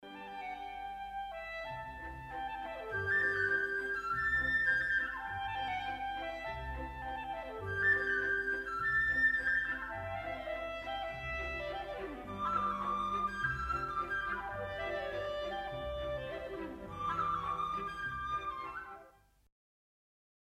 clarinetto e flauto
clarinetto_flauto.mp3